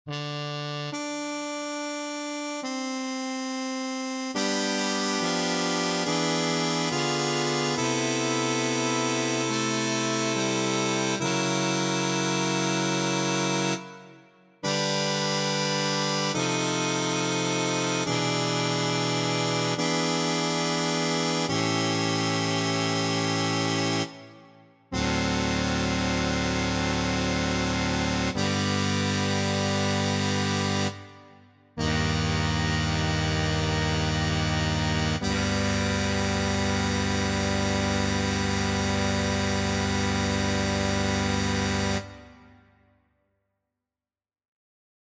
Key written in: G Major
How many parts: 4
Type: Barbershop
All Parts mix: